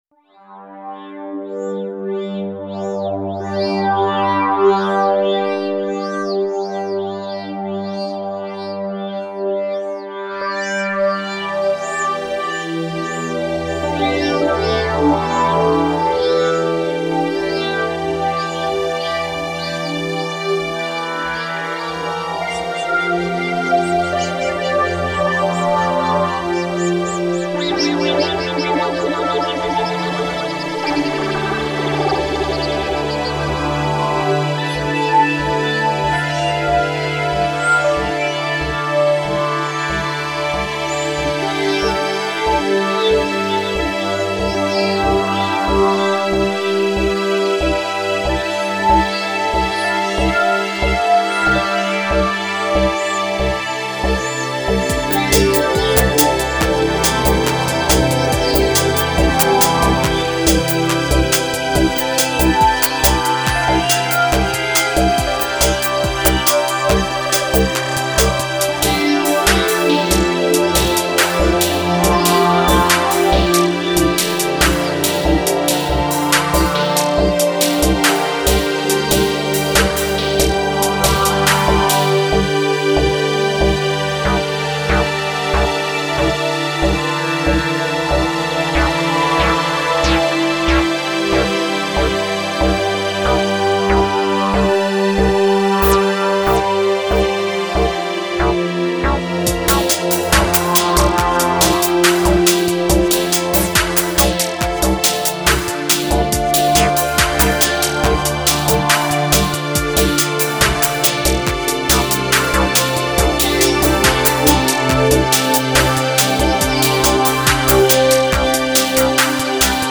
Setup: Live 4.0 & Reason 2.5
Style: Pop/Electronica
Orangestrom_Ambientmix.mp3